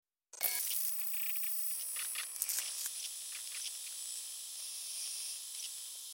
Звук включения прибора ночного видения вариант 2